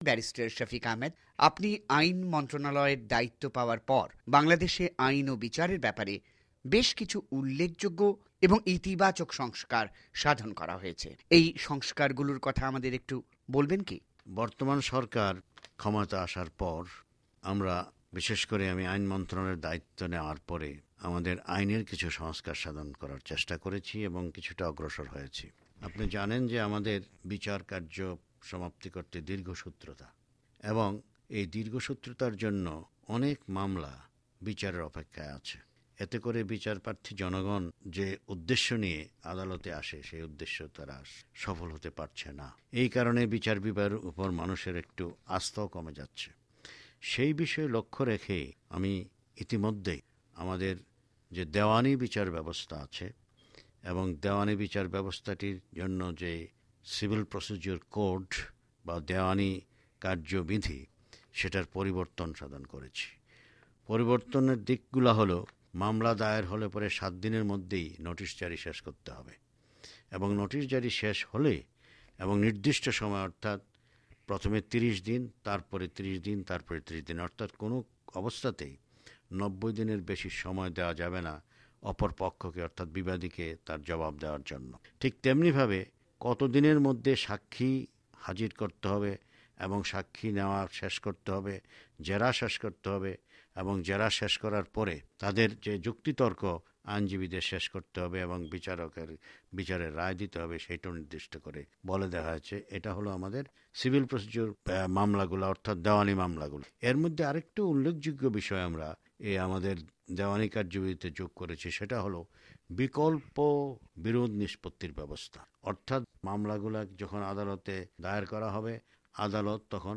ব্যারিস্টার শফিক আহমেদের সাক্ষাৎকার